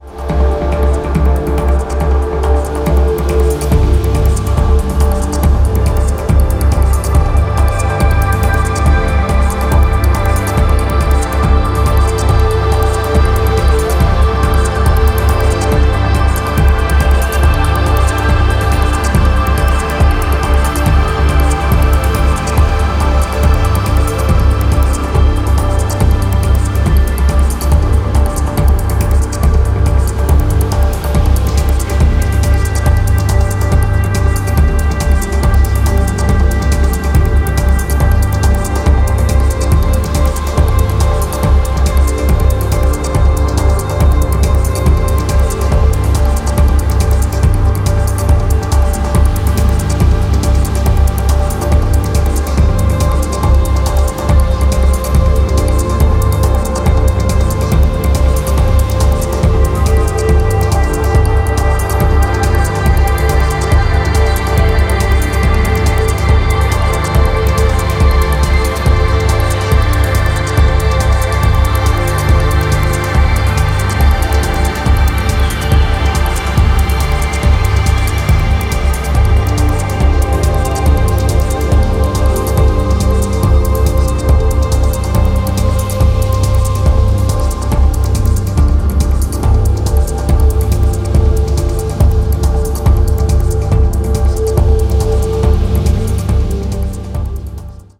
ディープ・テクノ推薦盤！